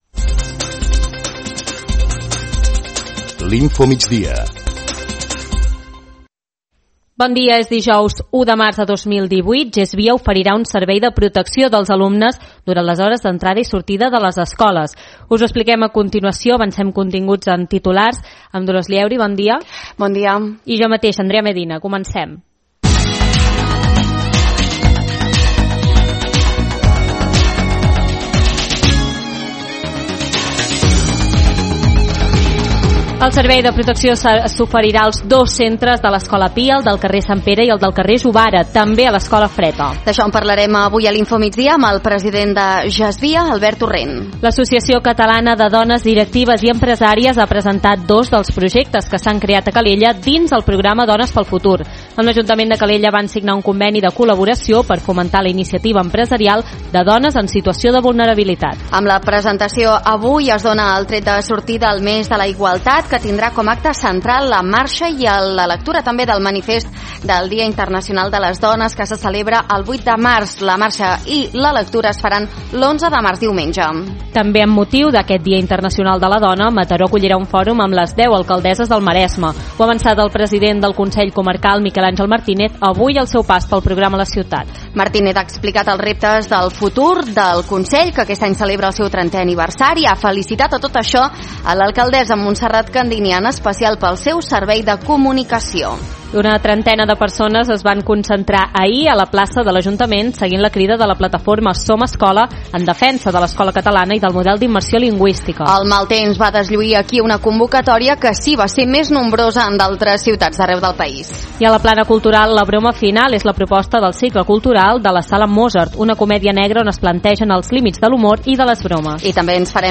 Preguntem al tinent d’Alcaldia de Mobilitat i Seguretat en què consistirà la prova pilot que es posarà en marxa la setmana vinent als dos centres de l’Escola Pia i al Freta en matèria de protecció escolar i amb personal de l’empresa municipal Gestvia. I també ens interessem pel programa Dones pel Futur que es va implantar l’any passat a Calella i que té per objectiu fomentar la iniciativa empresarial i l’autoocupació de dones en situació de vulnerabilitat.